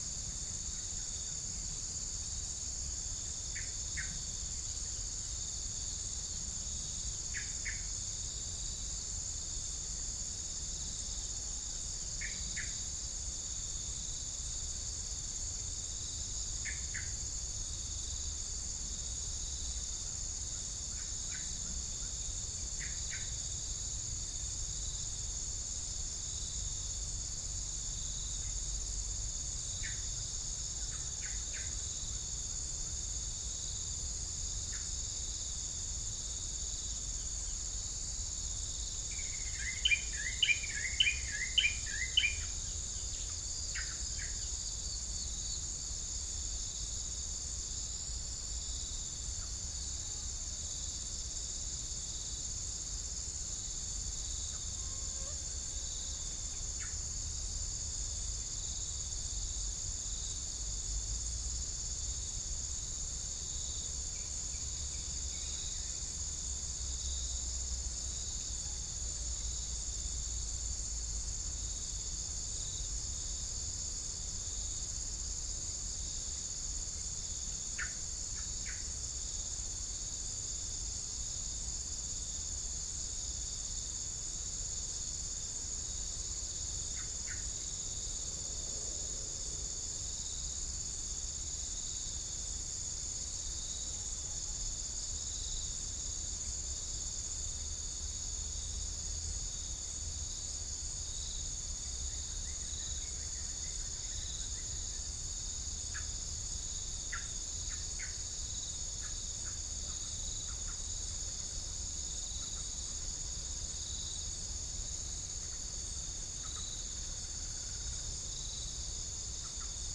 Pycnonotus goiavier
Todiramphus chloris
Halcyon smyrnensis
Orthotomus sericeus
Prinia familiaris